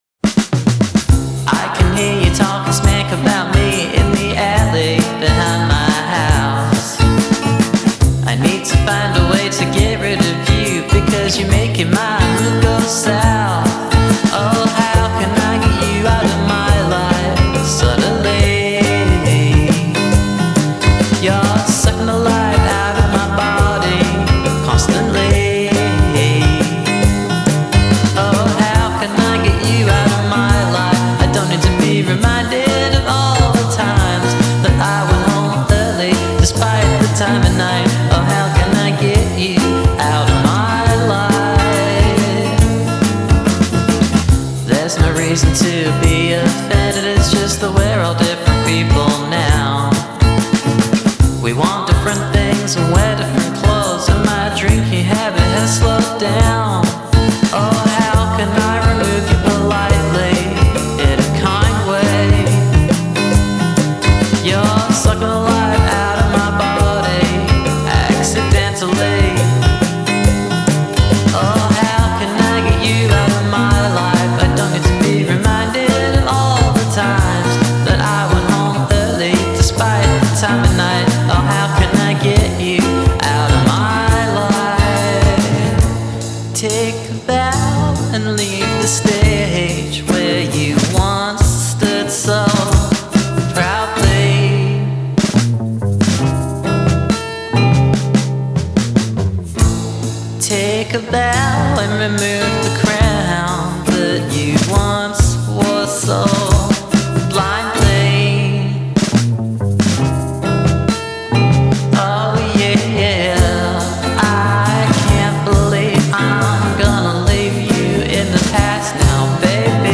an upbeat ‘ crappy-go-lucky ’ lo-fi gem
bright & breezy bedroom pop feel